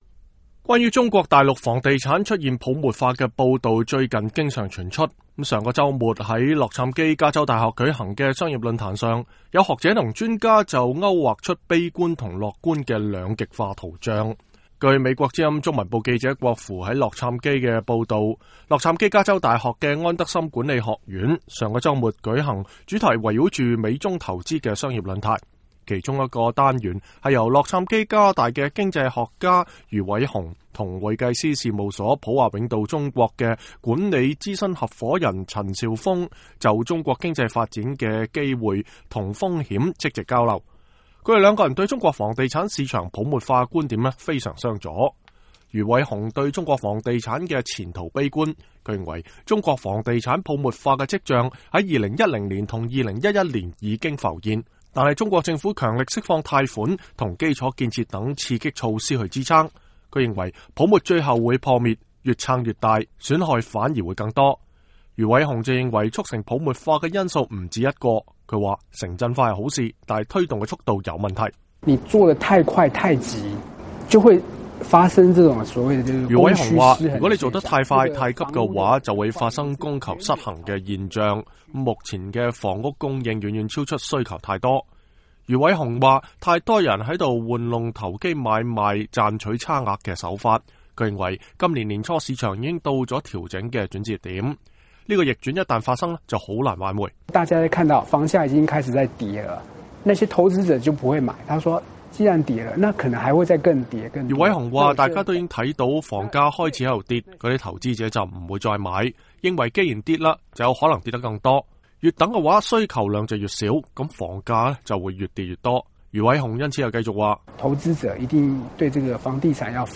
專家討論中國房地產泡沫問題